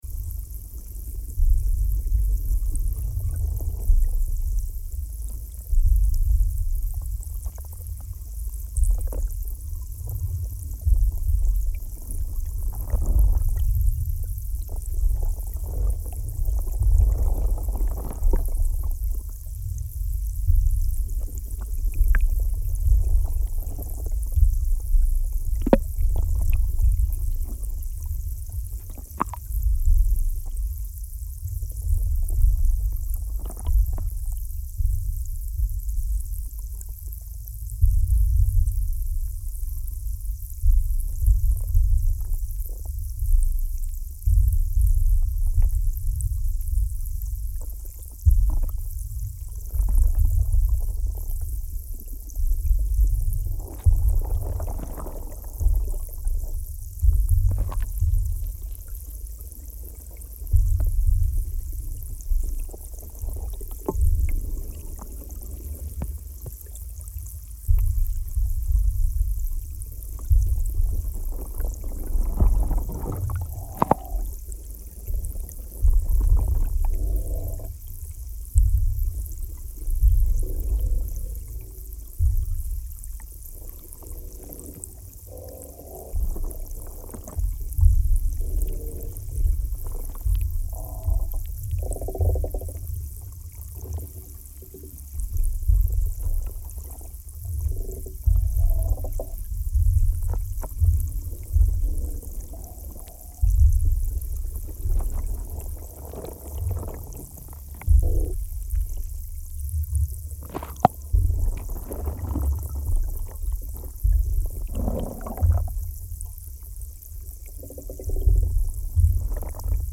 Soundscape Series